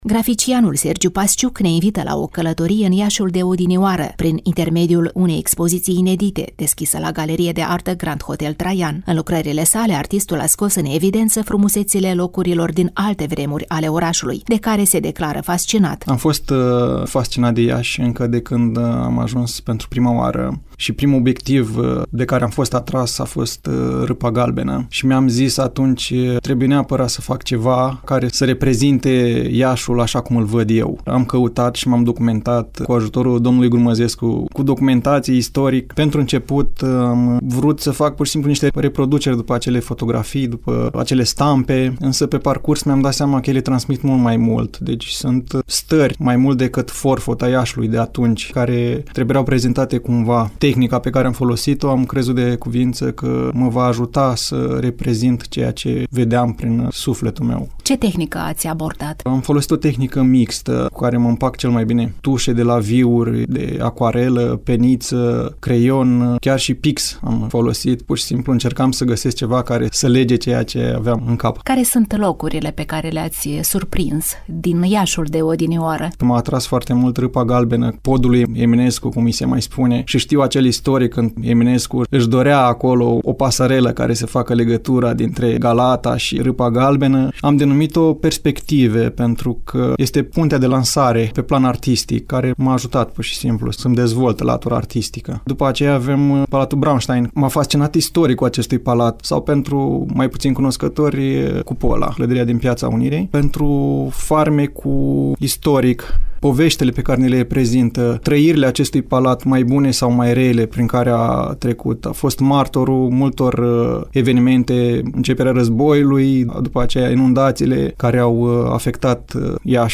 (FOTO) Iaşul de odinoară… poate! - Reportaj cultural